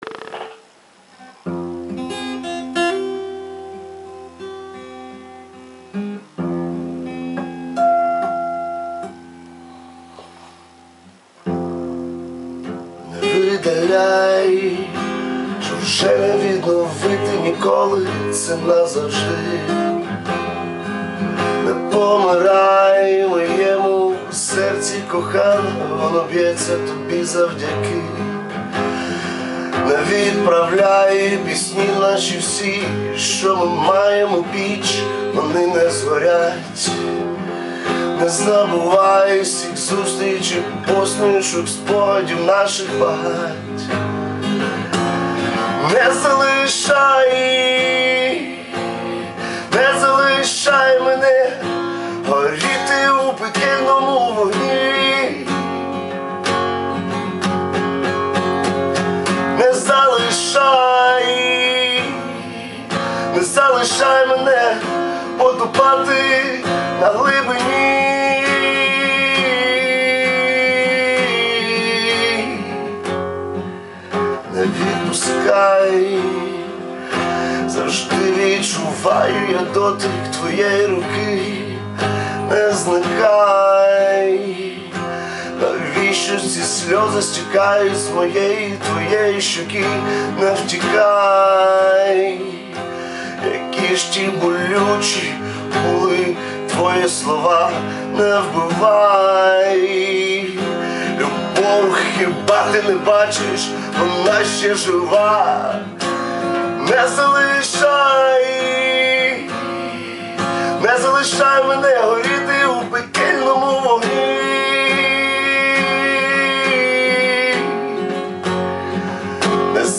ТИП: Пісня
СТИЛЬОВІ ЖАНРИ: Ліричний
ВИД ТВОРУ: Авторська пісня